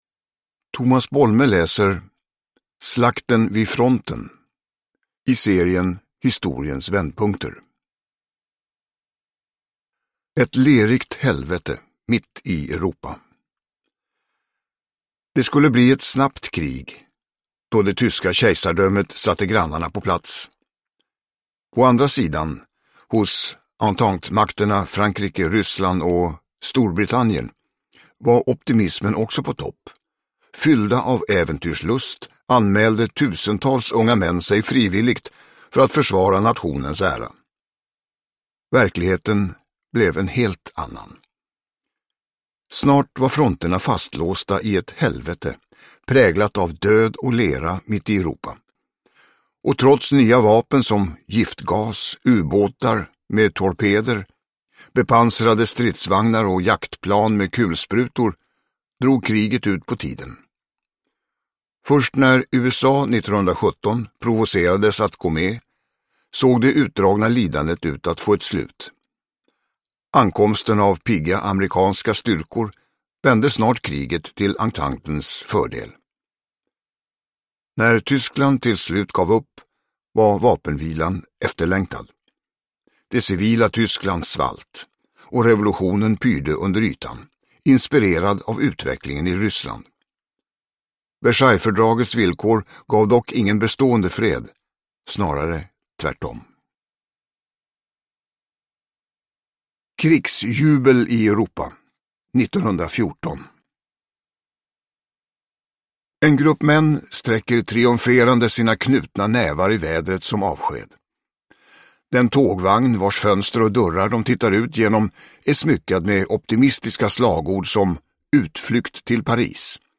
Slakten vid fronten – Ljudbok
Uppläsare: Tomas Bolme